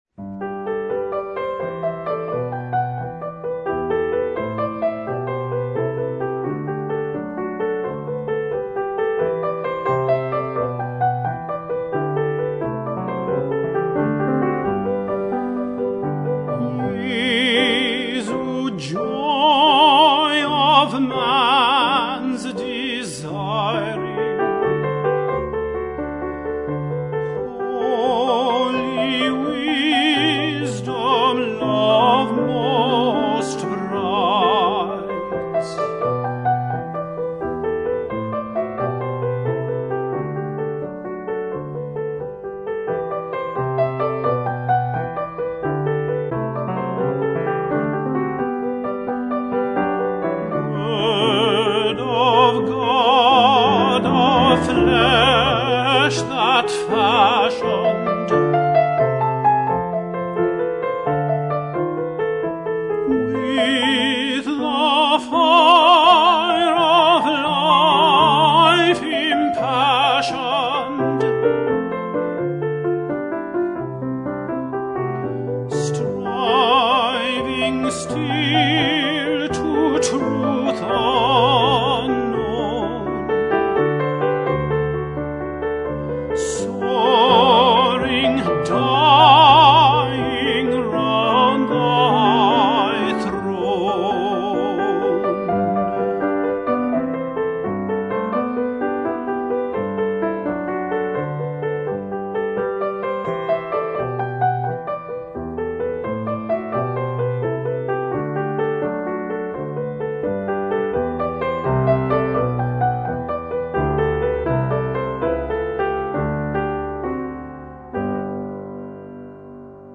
Chant et Piano